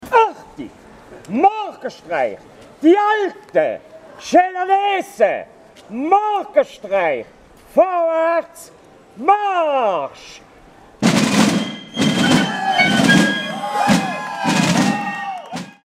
The order is given for the participants to march forwards at 4am on Monday morning.